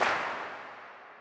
TS - CLAP (6).wav